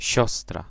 Ääntäminen
Ääntäminen Tuntematon aksentti: IPA: /ˈɕɔstra/ Haettu sana löytyi näillä lähdekielillä: puola Käännös Konteksti Ääninäyte Substantiivit 1. sister brittienglanti US UK 2. nurse US Suku: f .